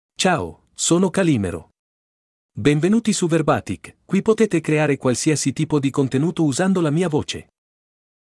Calimero — Male Italian (Italy) AI Voice | TTS, Voice Cloning & Video | Verbatik AI
Calimero is a male AI voice for Italian (Italy).
Voice sample
Listen to Calimero's male Italian voice.
Male